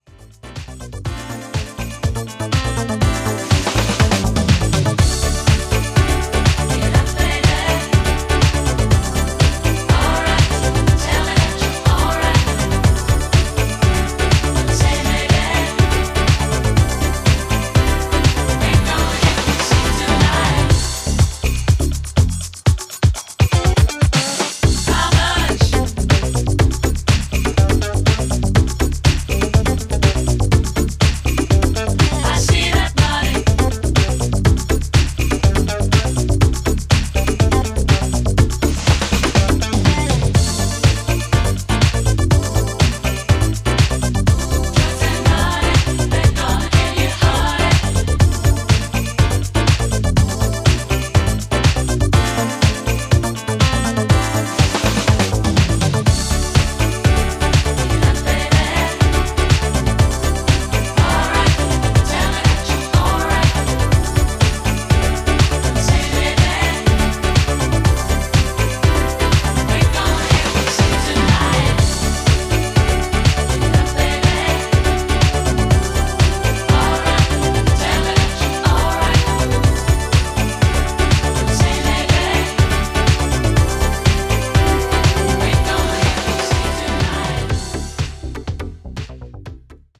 原曲の魅力を損なうことなくDJユースに仕立てたナイスな仕上がりとなっています。